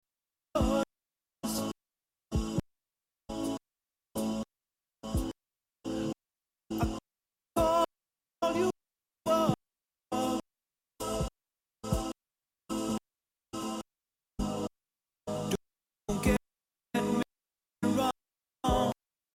Seit einer Woche habe ich einen Fehler, der nicht immer auftritt, eher sporadisch, mal nach 2 h streamen, mal nach 5 Stunden, mal nach 30 min.